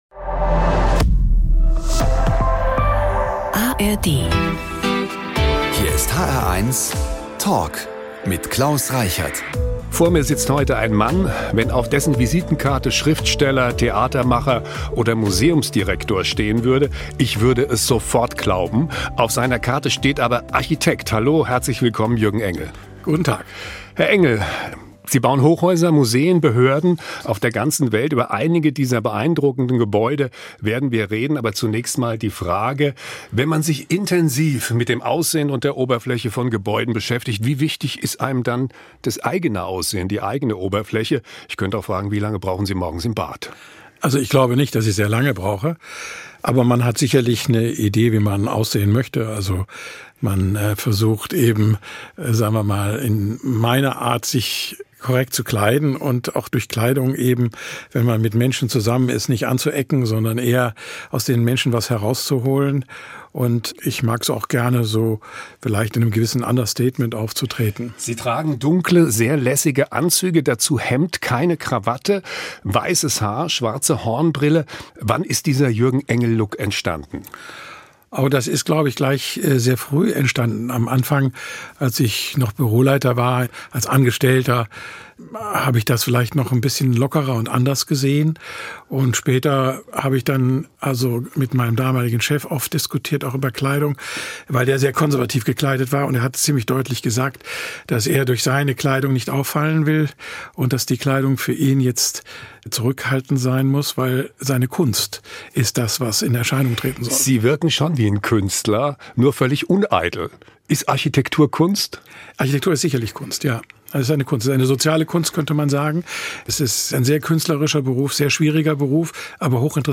Überraschende Einblicke und spannende Ansichten: Die hr1-Moderatoren im sehr persönlichen Gespräch mit Prominenten.